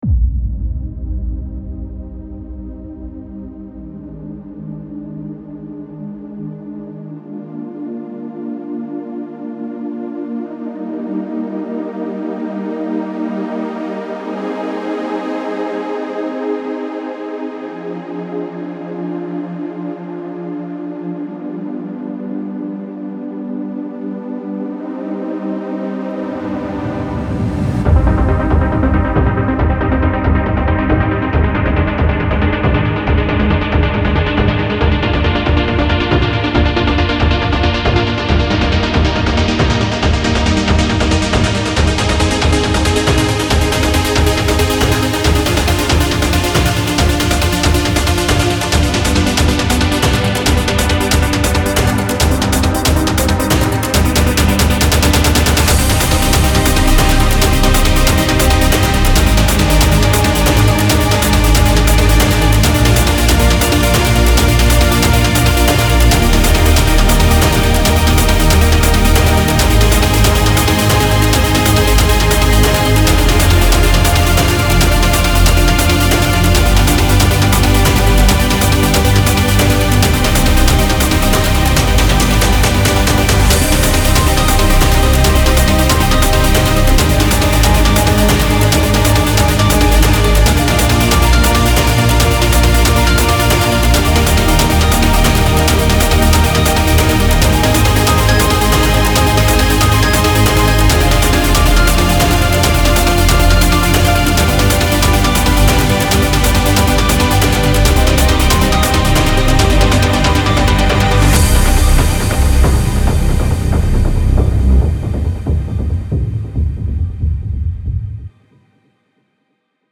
Intro пока такое.
Красиво, эпично!